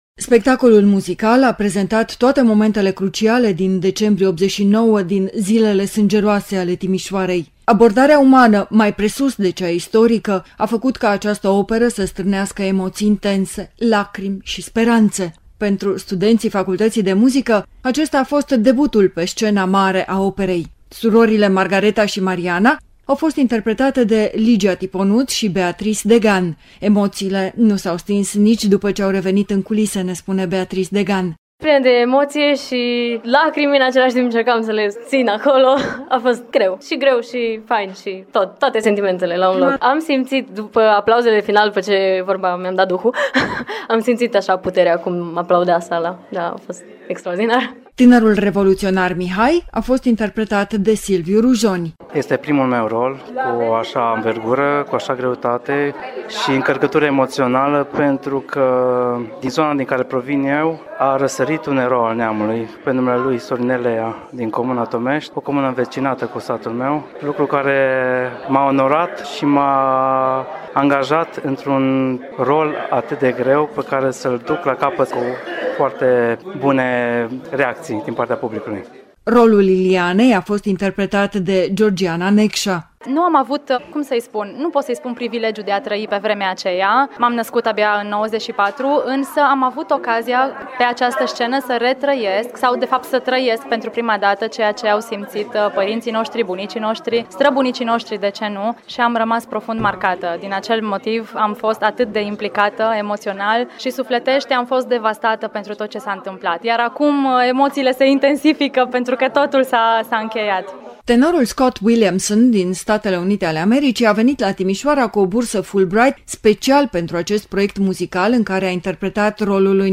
Minute îndelungate de aplauze susţinute nu au lăsat să coboare cortina peste premiera operei „Romanian Revolution 1989”.